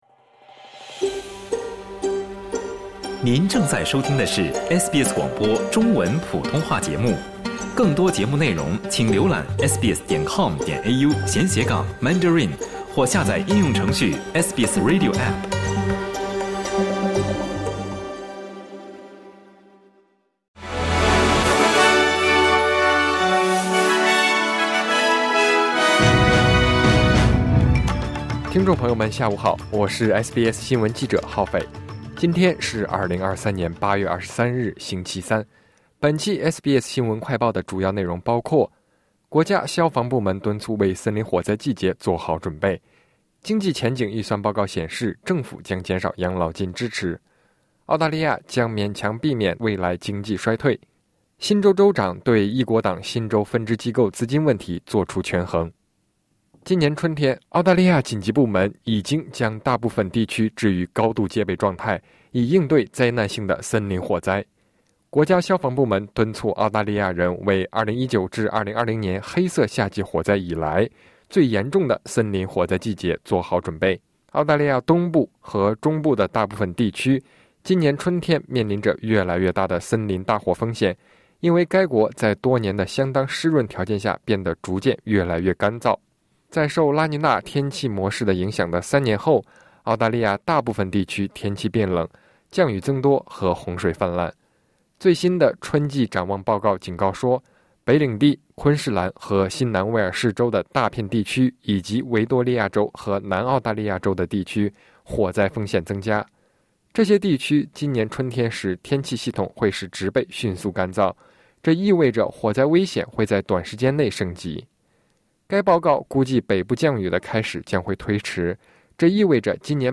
【SBS新闻快报】国家消防部门敦促为森林火灾季节做好准备